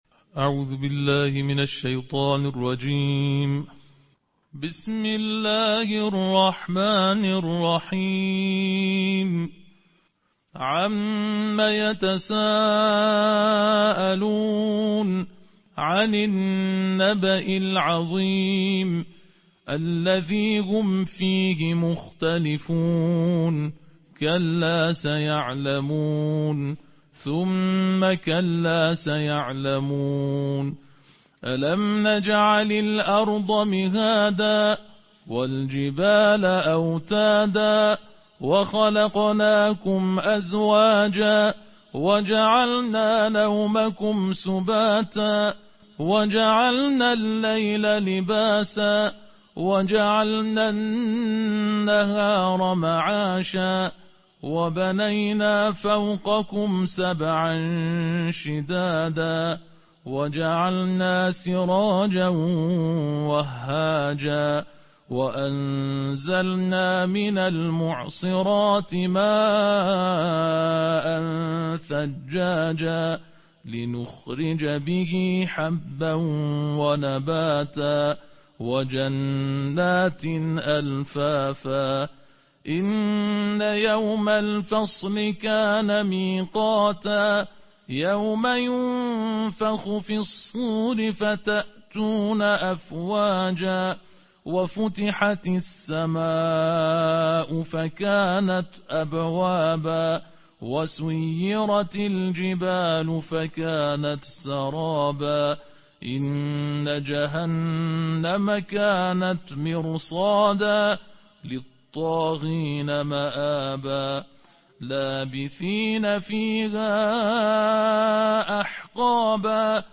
نړیوال قاریان،د قرآن کریم د دیرشمي (30) سپارې یا جزوې د ترتیل قرائت